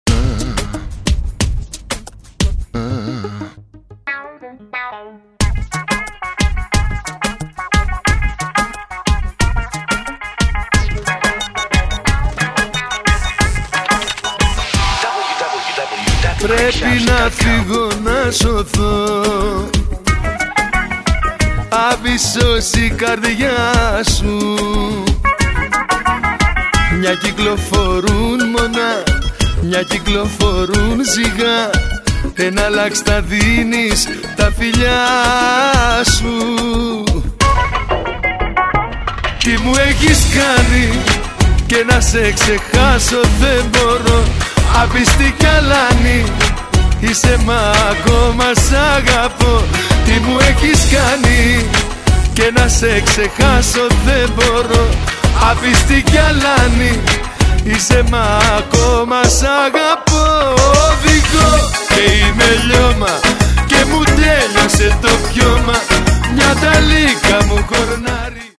Super chart hits remixed for great dance hits.